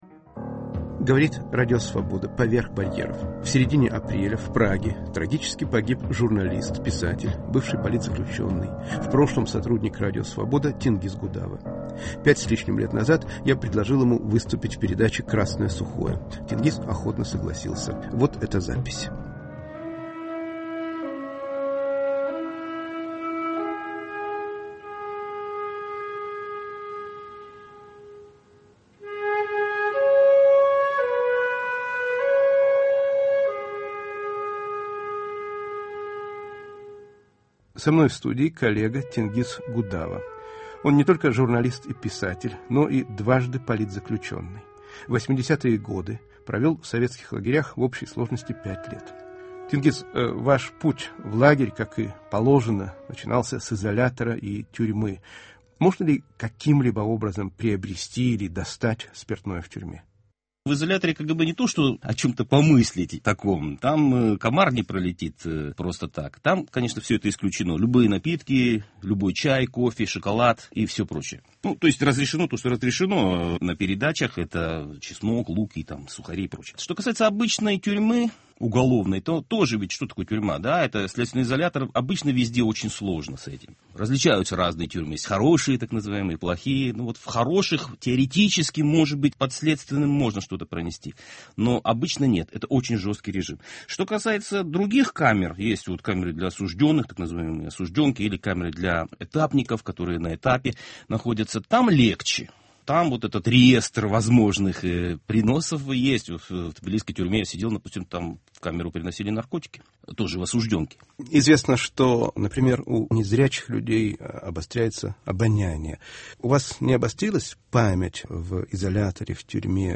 In Memoriam -разговор